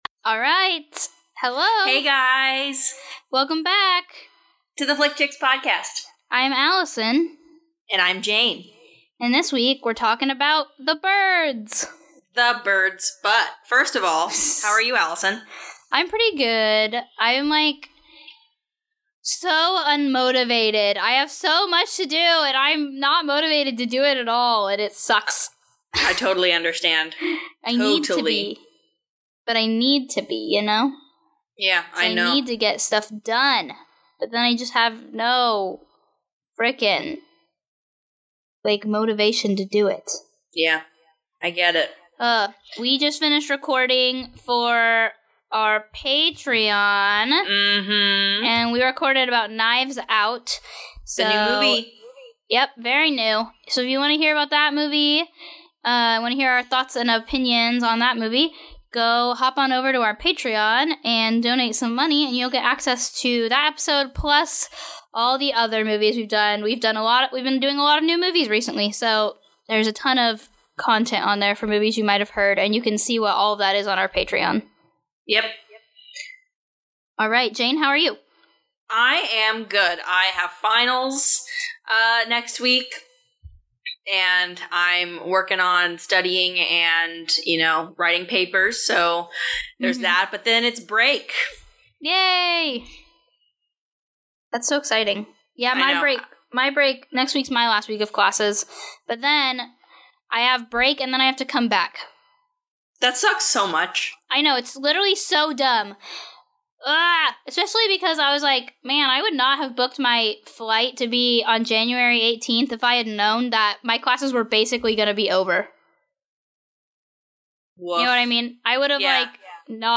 This week the girls chat about the Master of Suspense's 1963 classic, The Birds.